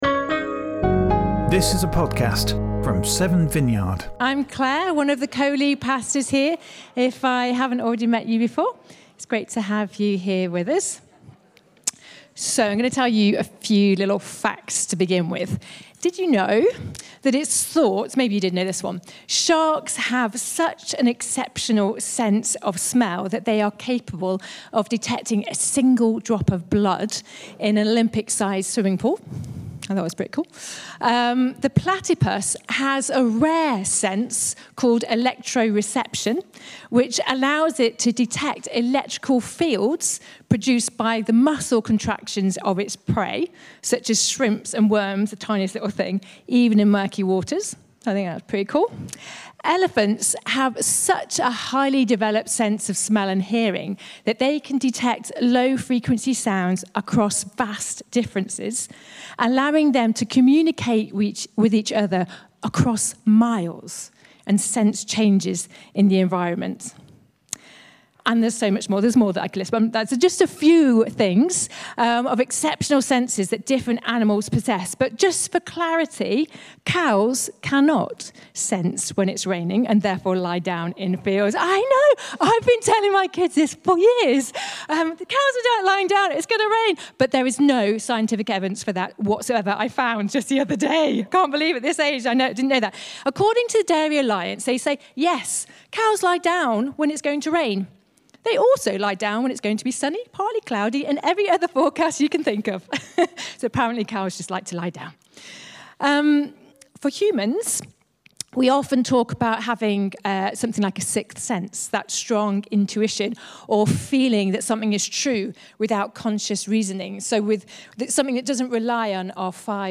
This talk contains lots of examples and ends with a contemplative exercise to help us focus on the Holy Spirit.